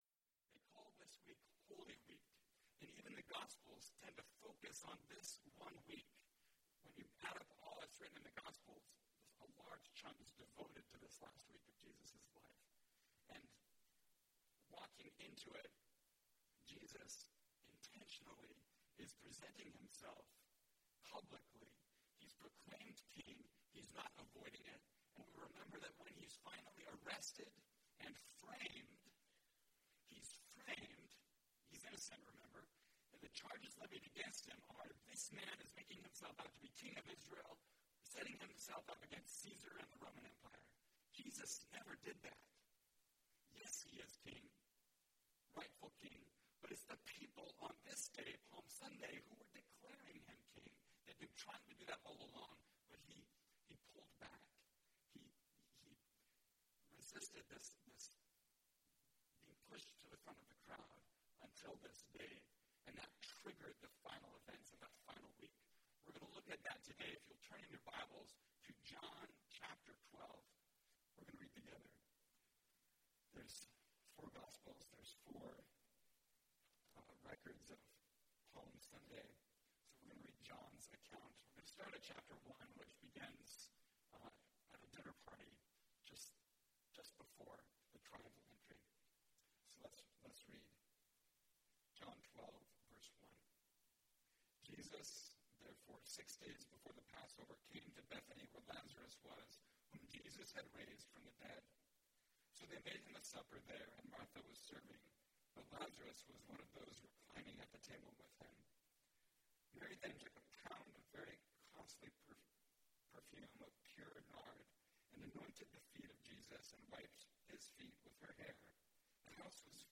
Worship the King (John 12:1-19) – Mountain View Baptist Church
Topical Message